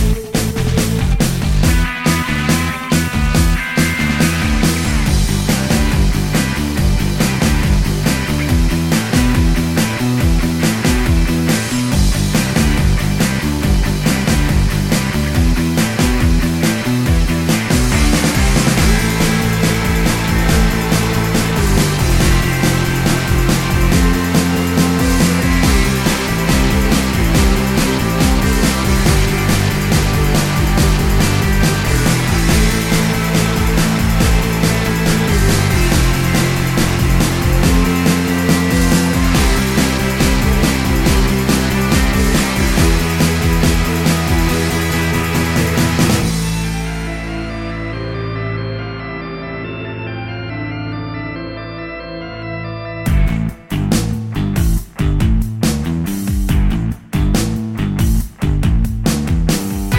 With Explicit Backing Vocals Rock 5:15 Buy £1.50